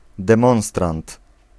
Ääntäminen
Ääntäminen Tuntematon aksentti: IPA: [dɛ̃ˈmɔ̃w̃strãnt] Haettu sana löytyi näillä lähdekielillä: puola Käännös Substantiivit 1. demonstrator Suku: m .